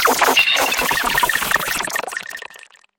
Mysterious alien technology activating with unfamiliar tones and organic-electronic hybrid sounds